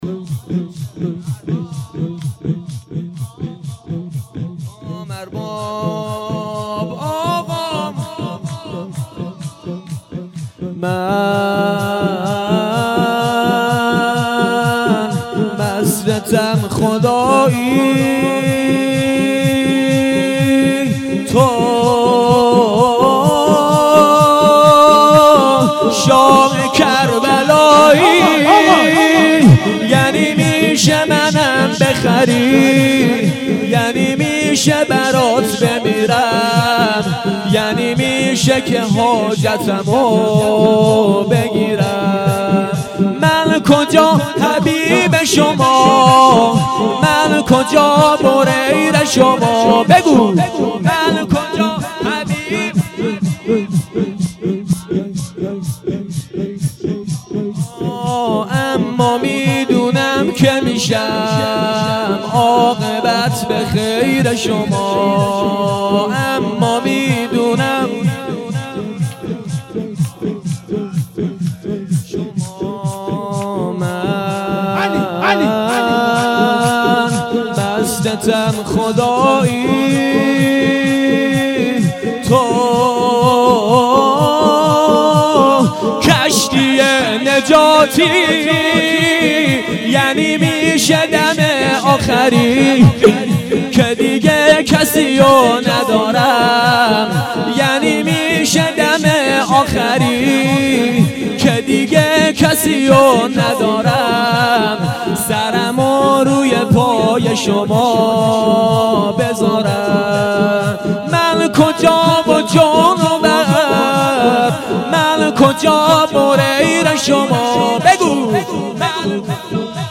شور زیبا من مستم خدایی